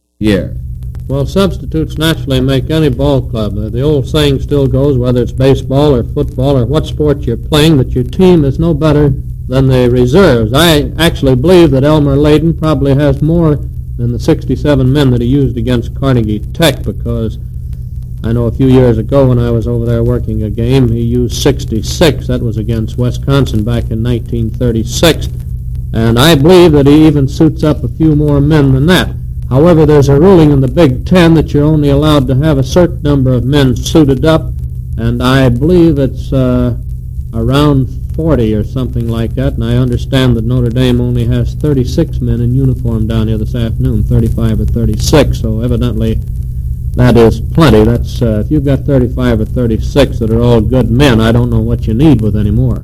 KMOX Laux, France aircheck · St. Louis Media History Archive
Original Format aircheck